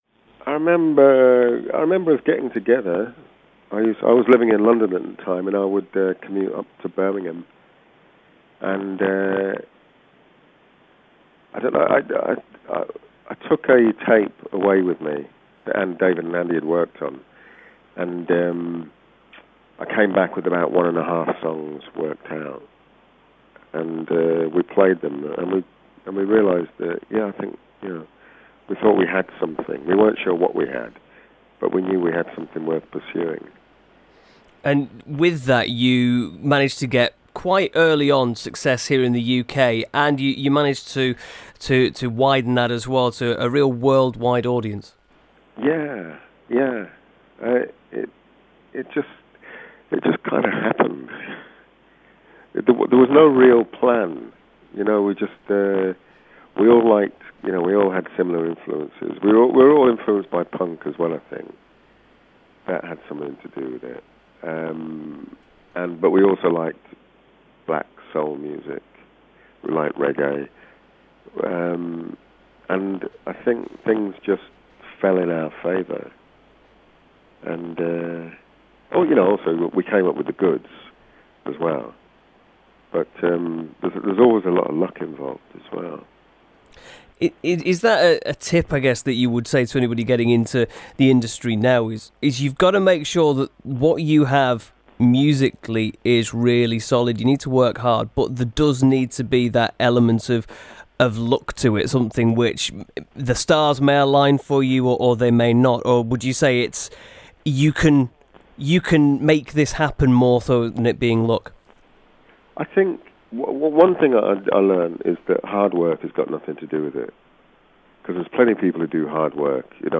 Proper Sport Mix Tape